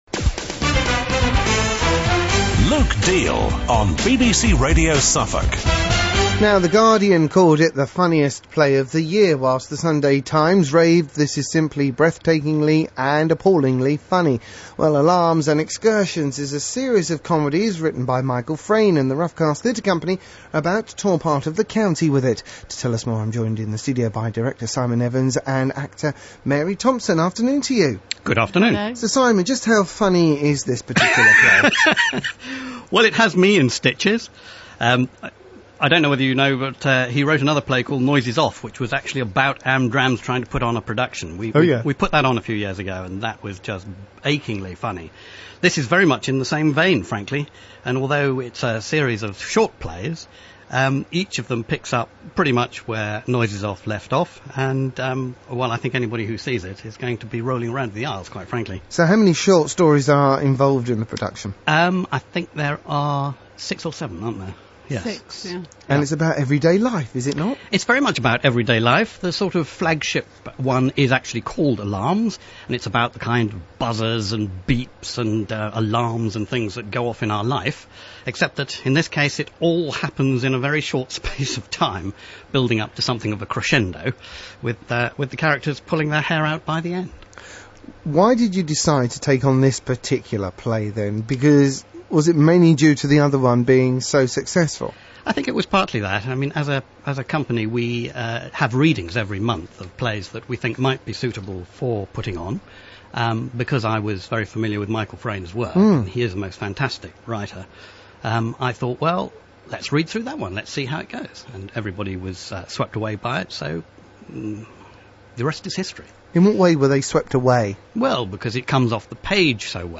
Please click here (2.5 MB MP3 file) for the full interview from 30th April